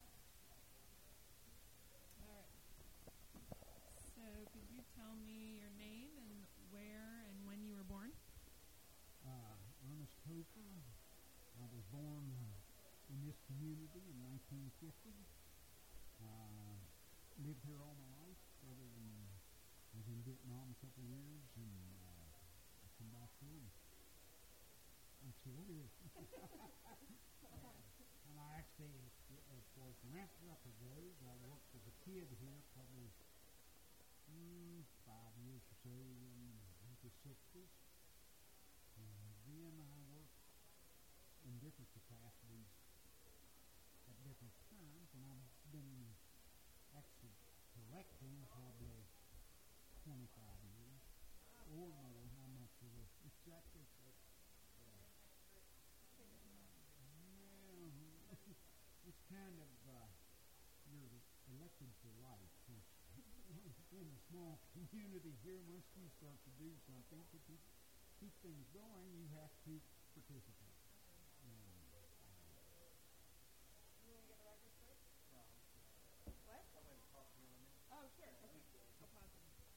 This interview is part of a series of interviews conducted with foodways practitioners in Helvetia, West Virginia, as part of the Helvetia Foodways Oral History Project in partnership with the Southern Foodways Alliance.
Helvetia (W. Va.)